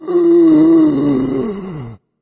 corpse_idle1.wav